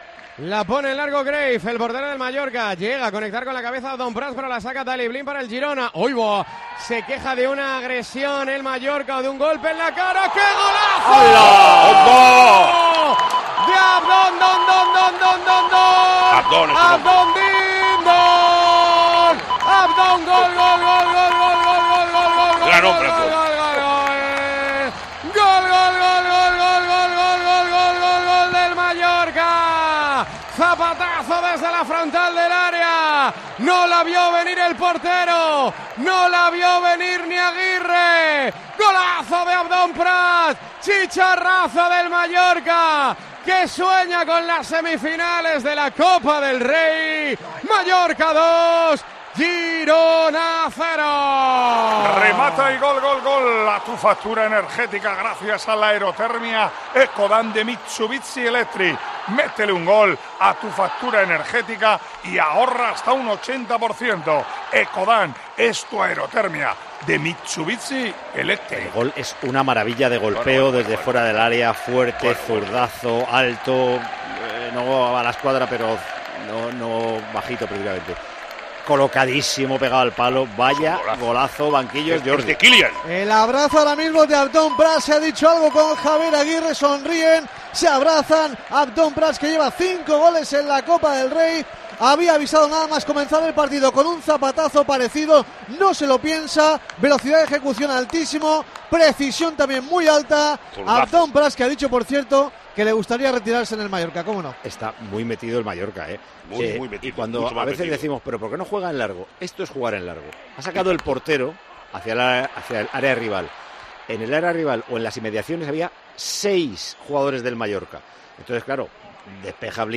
Así vivimos en Tiempo de Juego la retransmisión del Mallorca - Girona